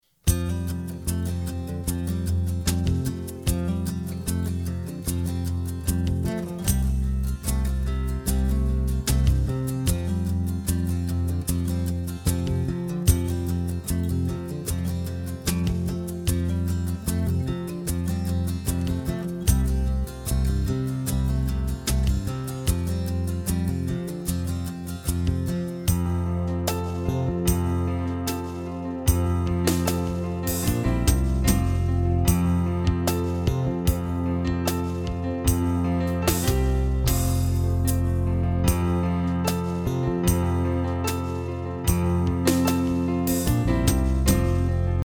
Listen to the instrumental track.